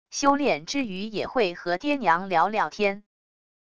修炼之余也会和爹娘聊聊天wav音频生成系统WAV Audio Player